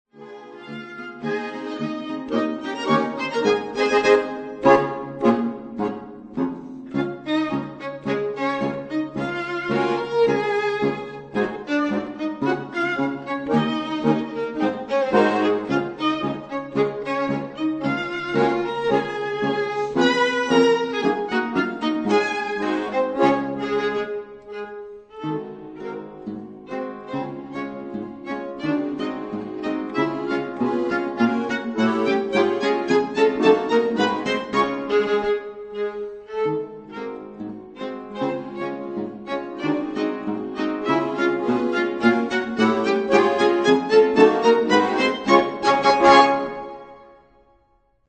** Quartett mit Knopfharmonika
Steinerner Saal, Musikverein Wien